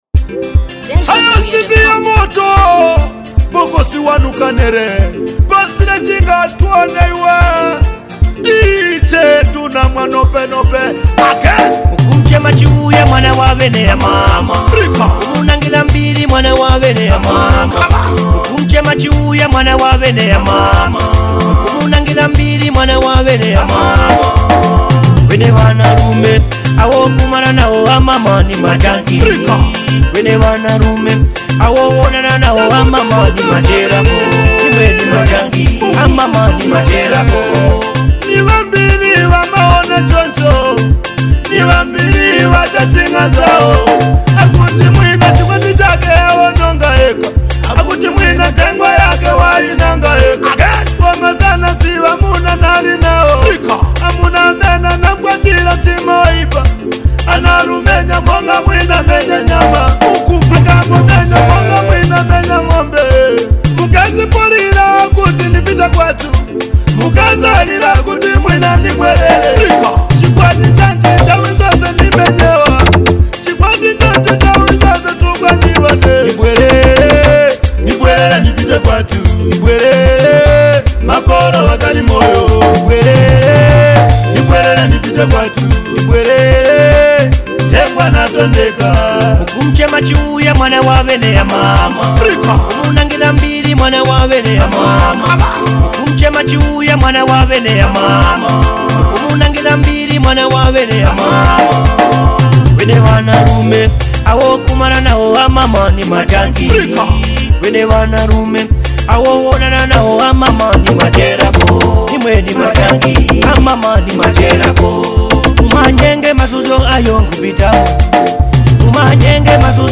deep and emotional song